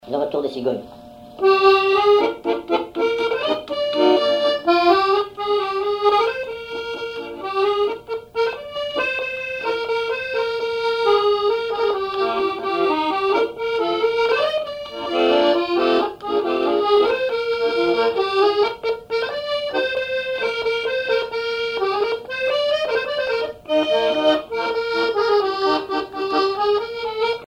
accordéon(s), accordéoniste
valse musette
Répertoire à l'accordéon chromatique